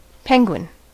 Ääntäminen
Ääntäminen US : IPA : [ˈpɛŋ.gwɪn]